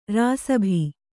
♪ rāsabhi